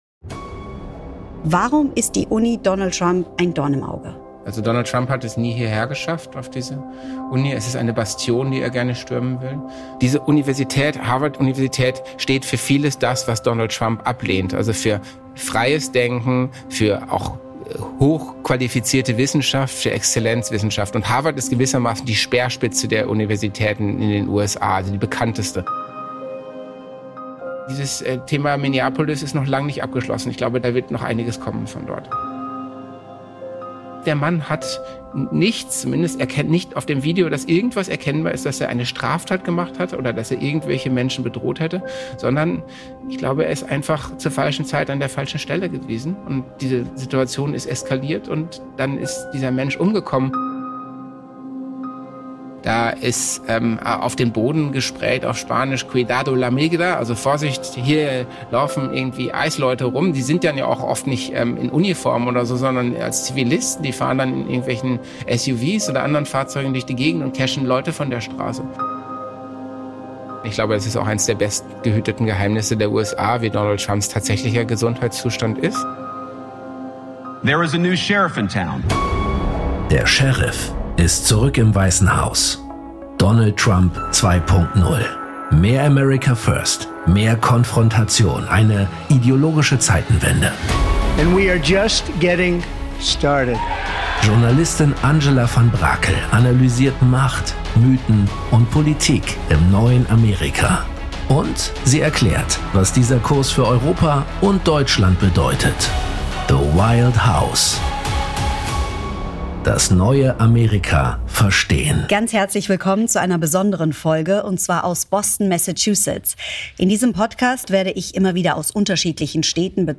trifft sie sich an der Harvard University.